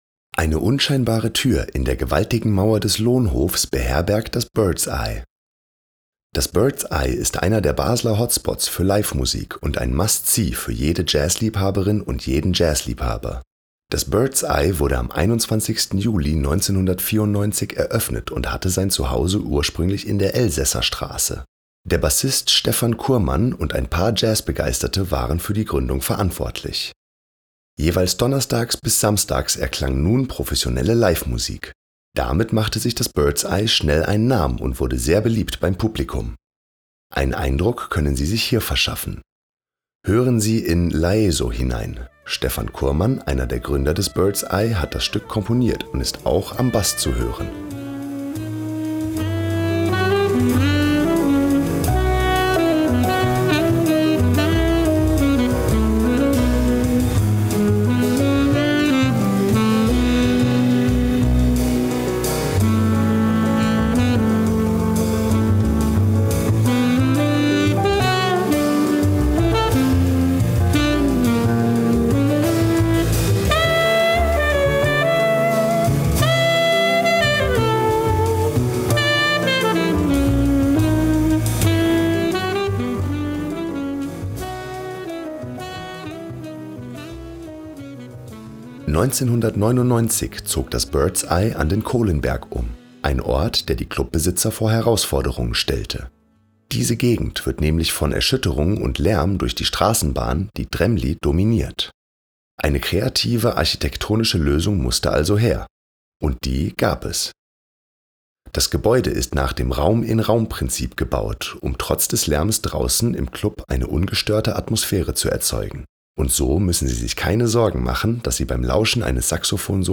QUELLEN der Musikbeispiele: